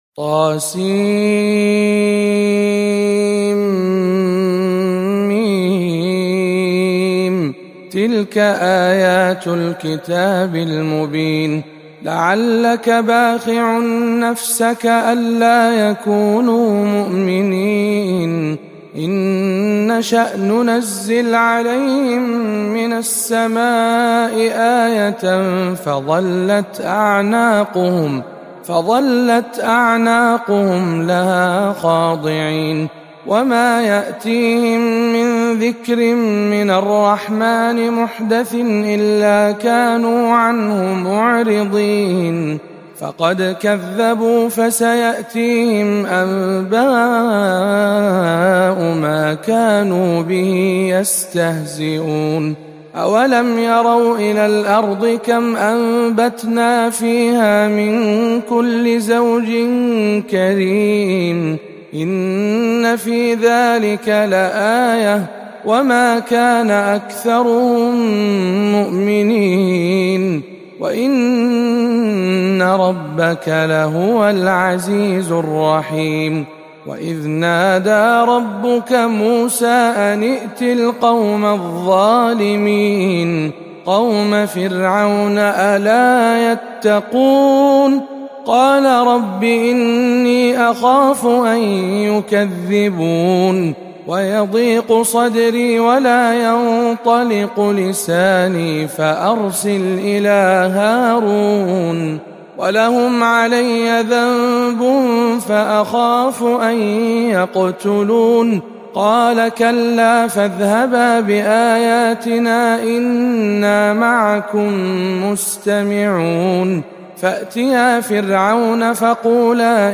سورة الشعراء بجامع معاذ بن جبل بمكة المكرمة